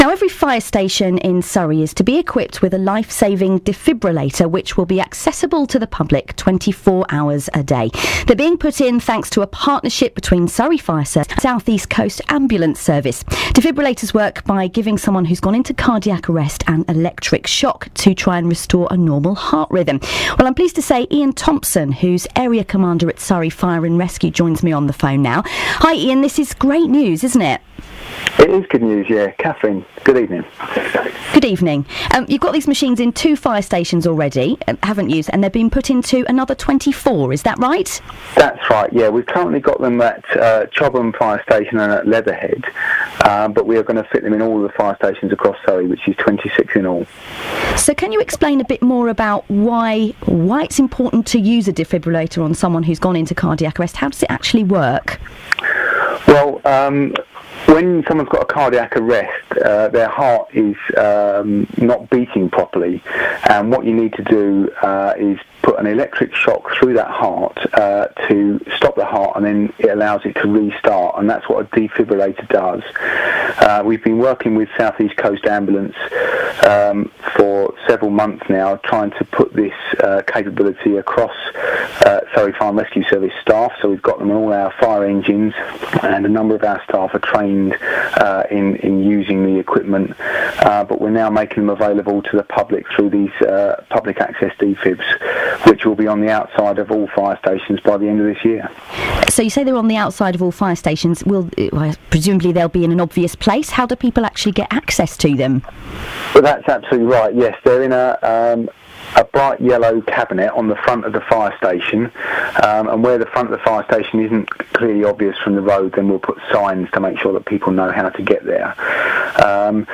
discussing the initiative on BBC Surrey.
Audio courtesy of BBC Surrey.